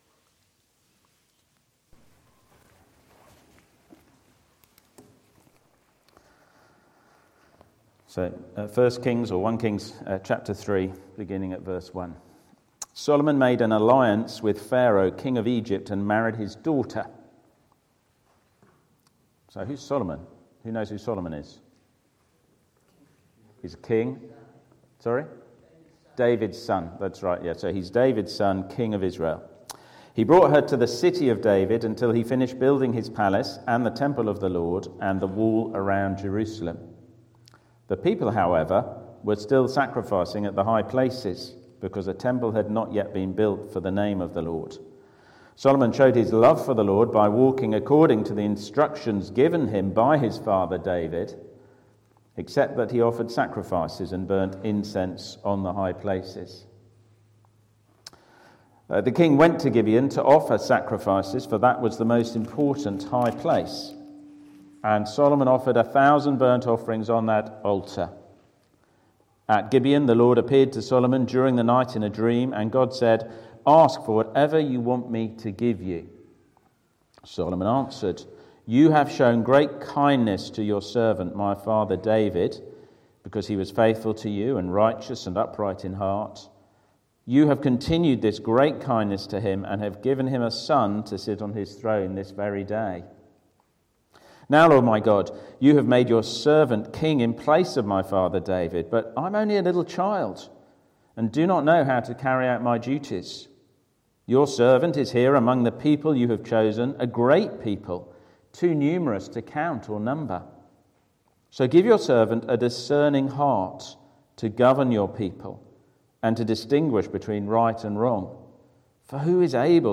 Sermons – Dagenham Parish Church